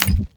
ClickSound.mp3